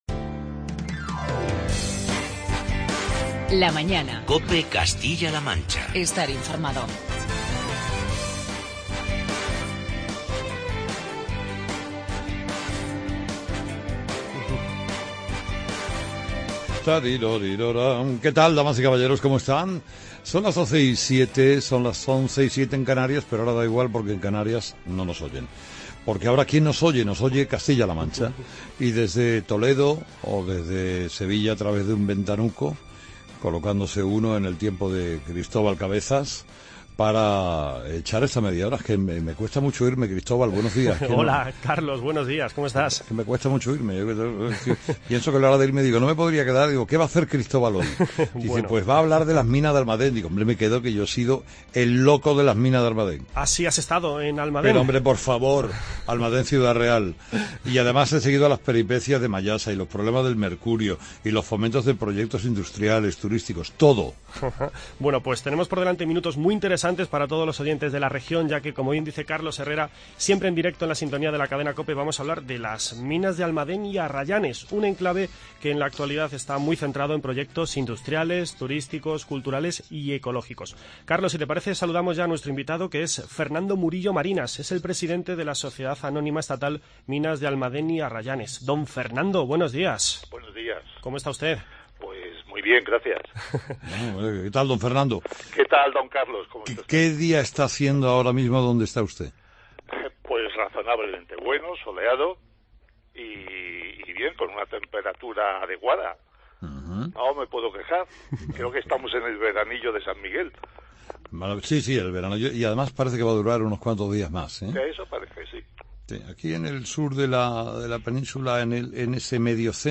Carlos Herrera charla con